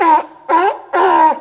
seal.wav